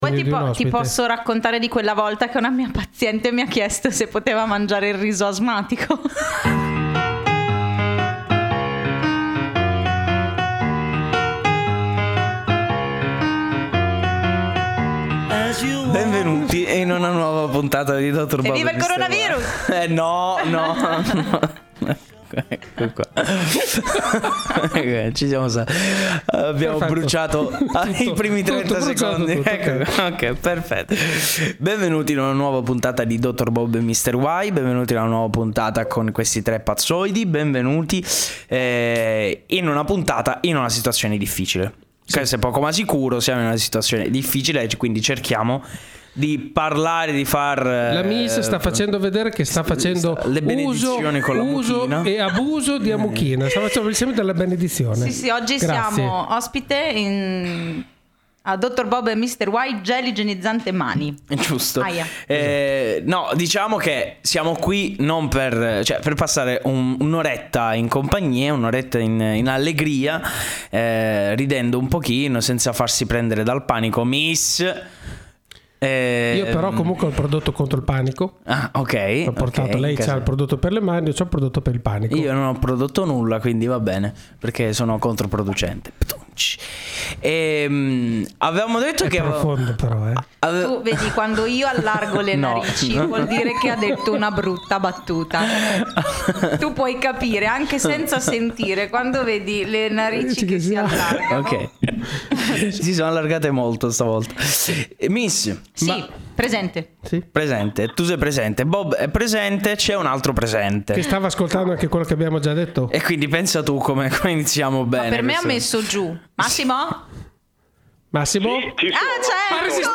INTERVISTA FLYWEB RADIO
Questa intervista telefonica mi è stata fatta sabato 7 marzo.
Giustamente il tono è scherzoso e goliardico.